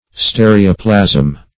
Search Result for " stereoplasm" : The Collaborative International Dictionary of English v.0.48: Stereoplasm \Ste"re*o*plasm\, n. [Stereo- + Gr.